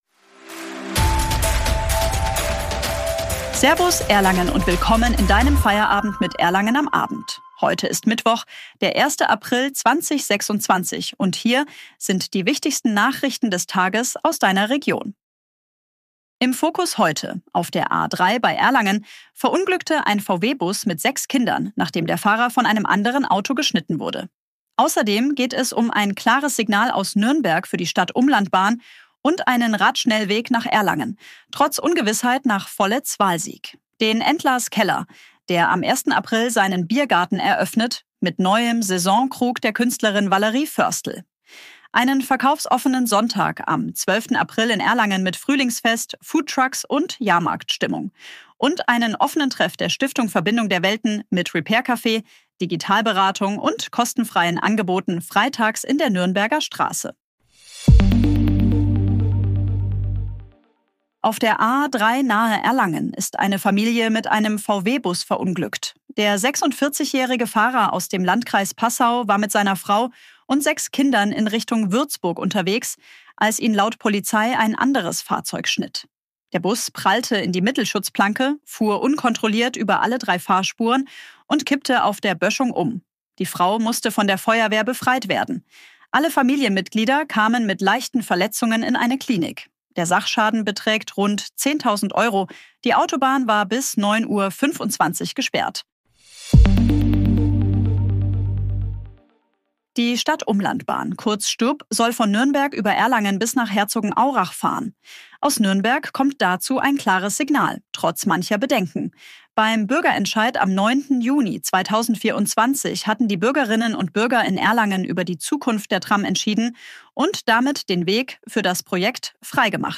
Guten Abend Erlangen! Willkommen zu deinem täglichen News-Update
Nürnberger Straße Dieses Update wurde mit Unterstützung künstlicher
Intelligenz auf Basis von redaktionellen Texten erstellt.